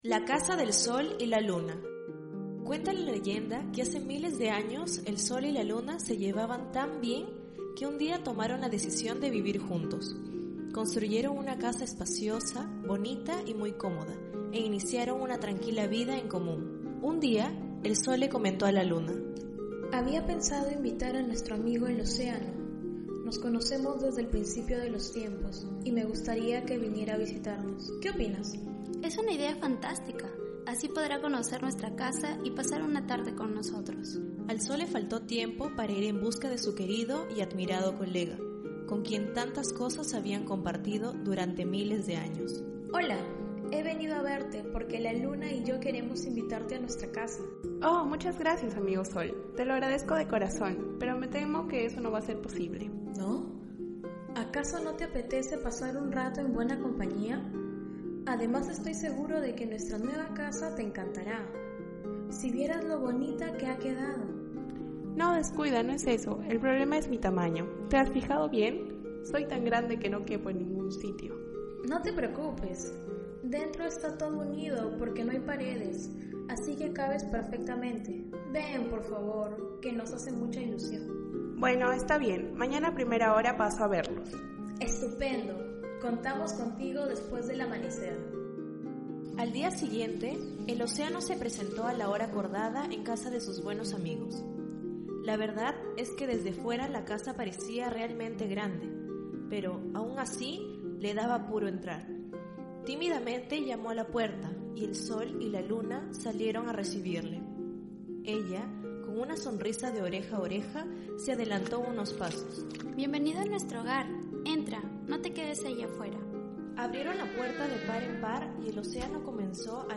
Alumnas de la USAT.